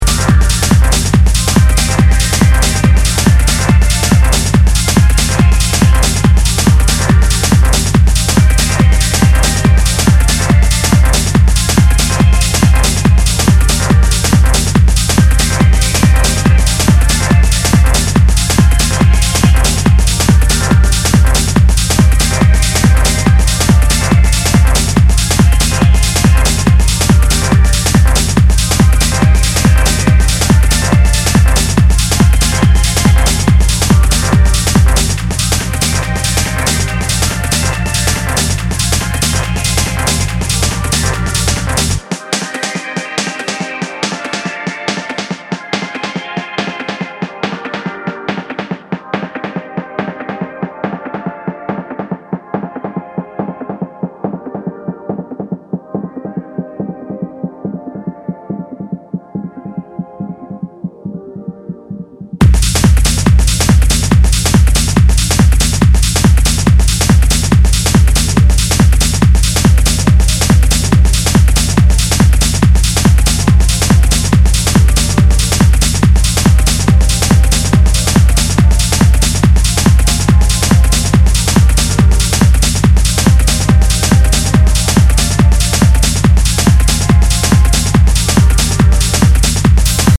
driving, furious and old-school influenced techno